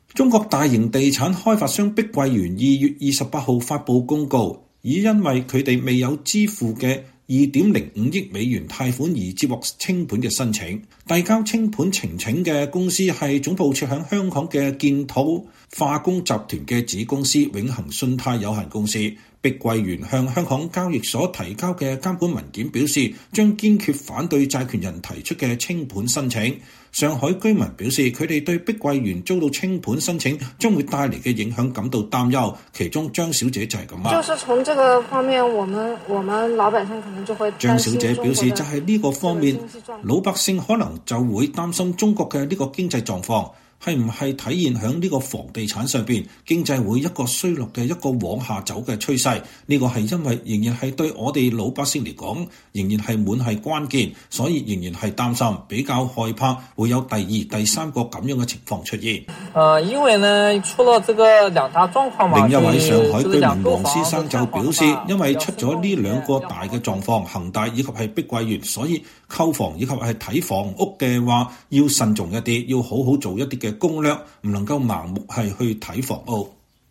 上海居民表示，他們對碧桂園遭清盤申請將帶來的影響感到擔憂。